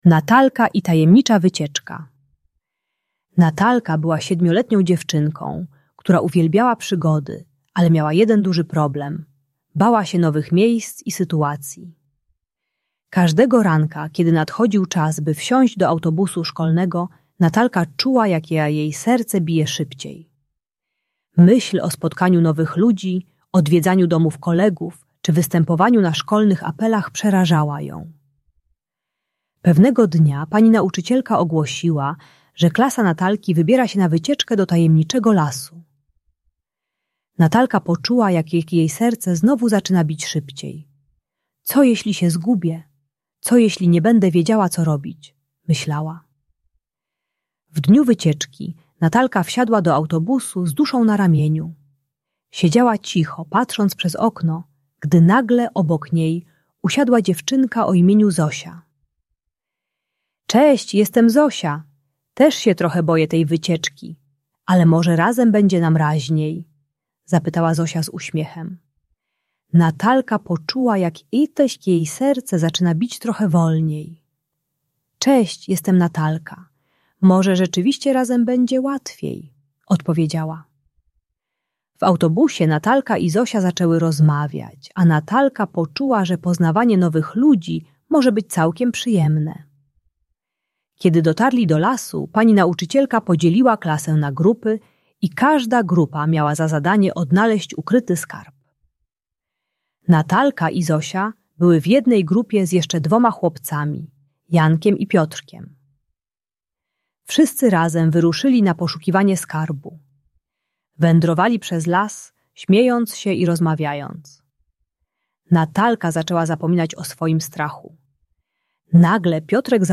Bajka dla dziecka które boi się nowych sytuacji i miejsc, przeznaczona dla dzieci 6-8 lat. Ta audiobajka o lęku i wycofaniu pomaga dziecku zrozumieć, że nowe doświadczenia mogą być fascynujące. Uczy techniki szukania wsparcia u rówieśników i małych kroków w pokonywaniu strachu przed nieznanym.